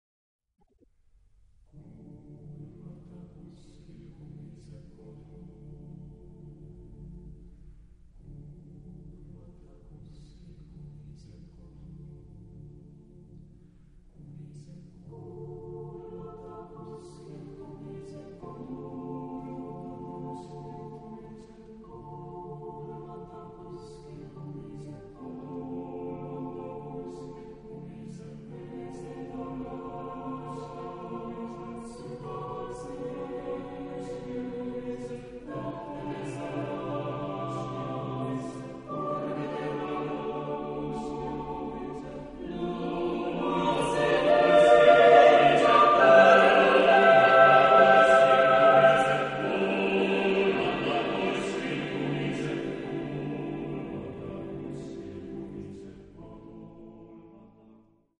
Epoque: 20th century
Genre-Style-Form: Partsong ; Secular
Type of Choir: SSAATTBB  (8 mixed voices )